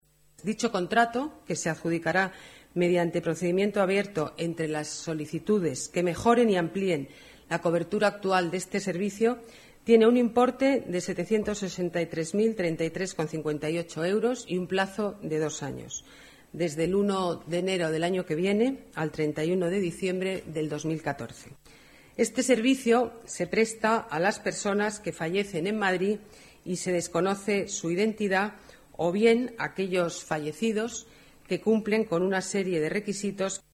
Nueva ventana:Declaraciones de la alcaldesa, Ana Botella